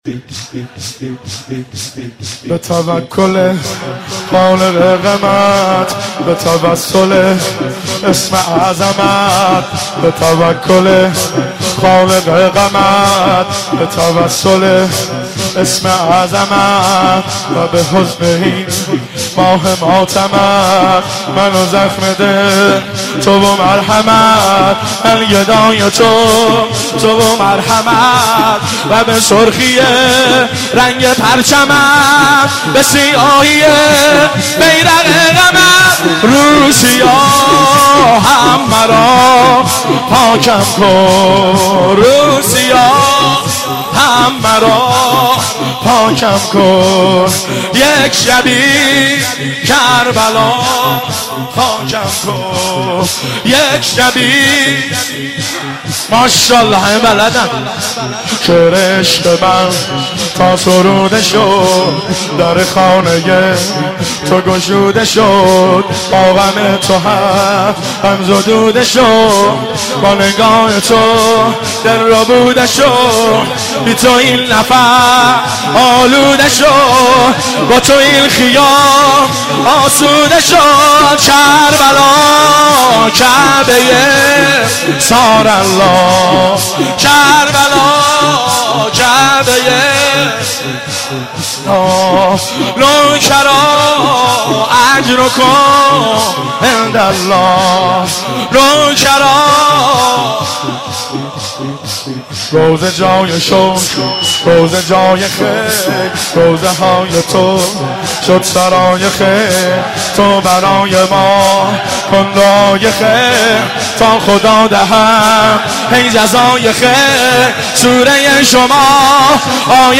با نوای گرم
در هیئت بین الحرمین تهران اجرا شده است